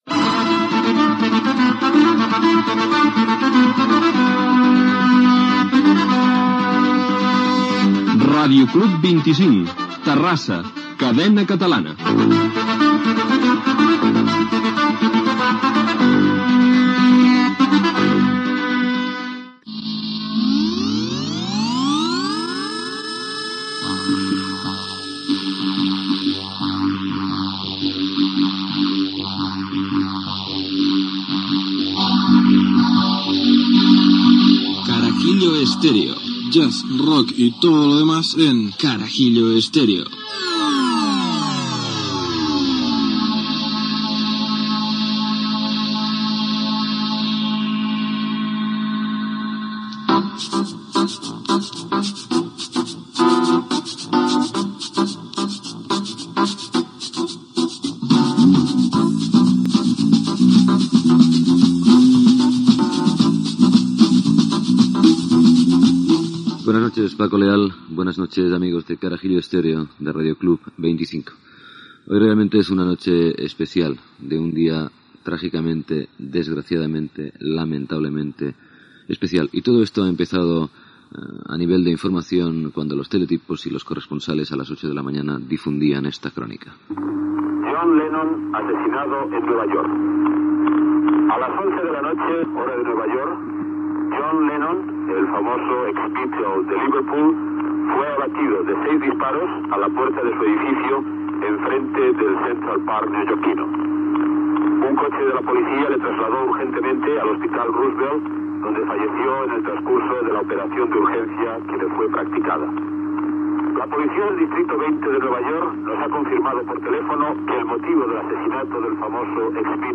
Indicatiu de l'emissora, careta del programa, presentació, notícia de l'assassinat de John Lennon a Nova York i record a la seva figura musical.
Musical